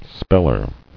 [spell·er]